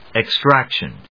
音節ex・trac・tion 発音記号・読み方
/ɪkstrˈækʃən(米国英語), eˈkstrækʃʌn(英国英語)/